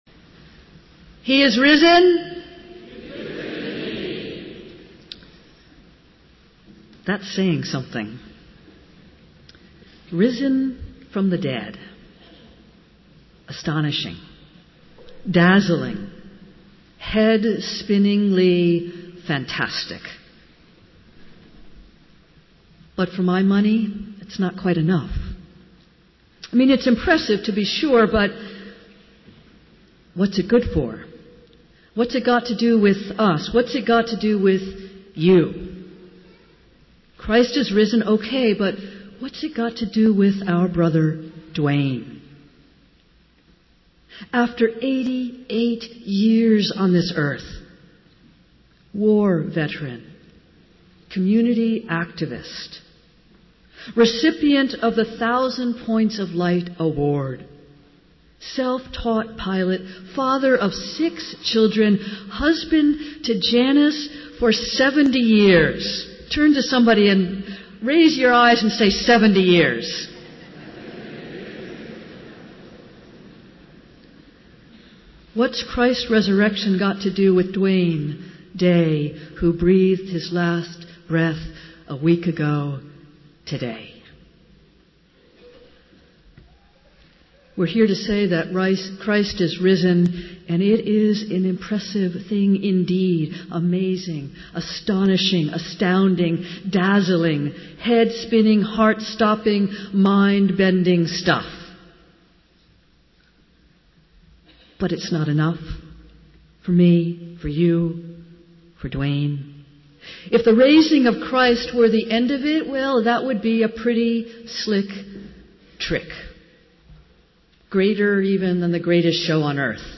Festival Worship - Easter Sunday